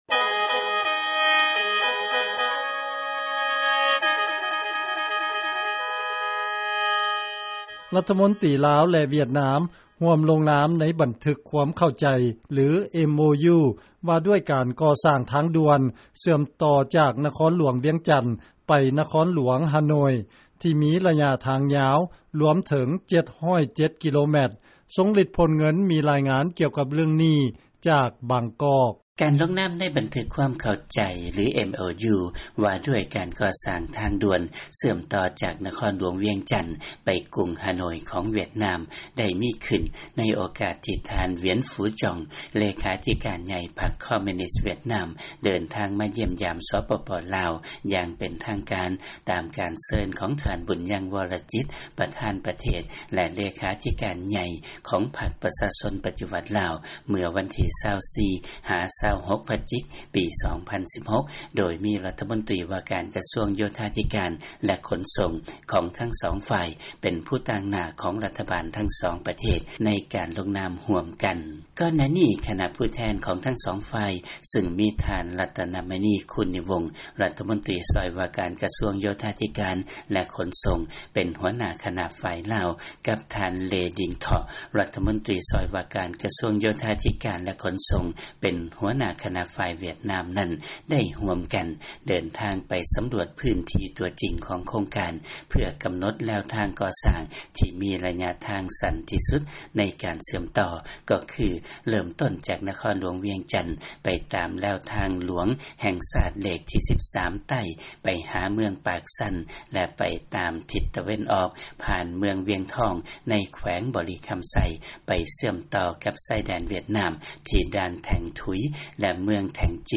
ເຊີນຟັງລາຍງານ ລມຕ ລາວ ແລະຫວຽດນາມ ຮ່ວມລົງນາມ ໃນບັນທຶກຄວາມເຂົ້າໃຈ ວ່າດ້ວຍການກໍ່ສ້າງ ທາງດ່ວນ ວຽງຈັນ ຫາ ຮາໂນ່ຍ